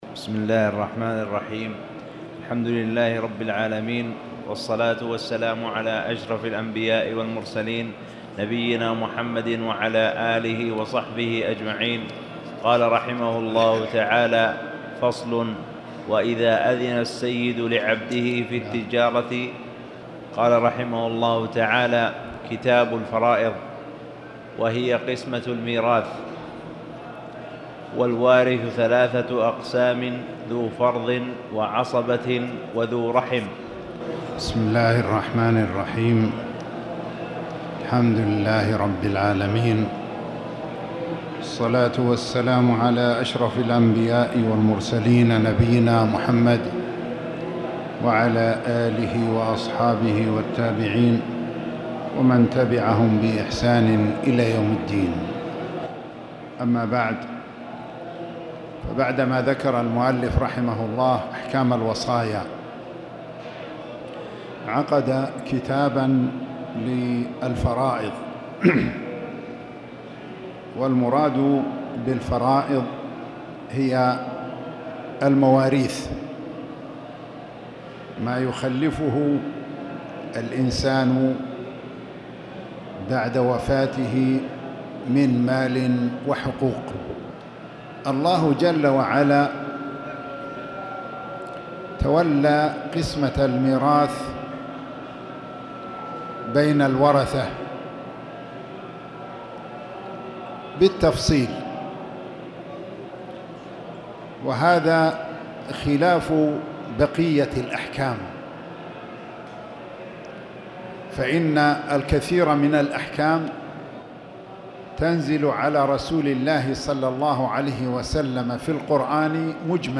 تاريخ النشر ٢٨ محرم ١٤٣٨ هـ المكان: المسجد الحرام الشيخ